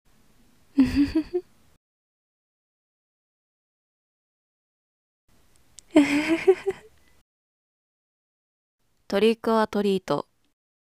笑い声